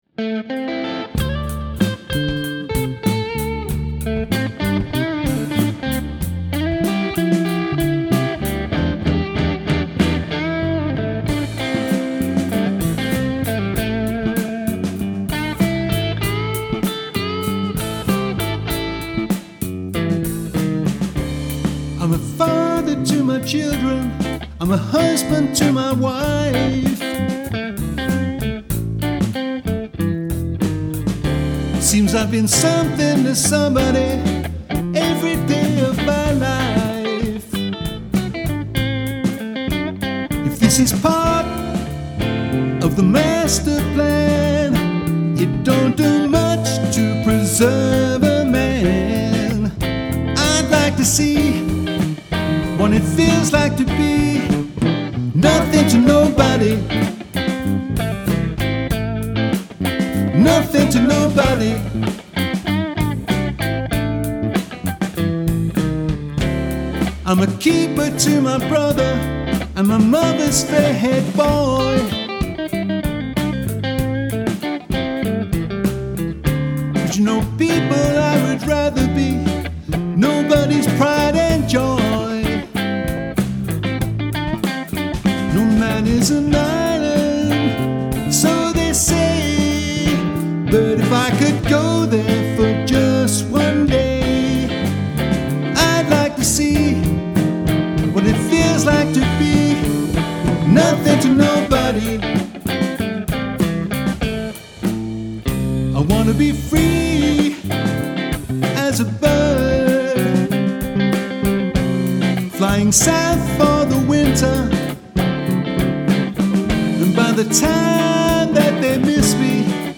Nous jouons essentiellement du rock, funk et reggae.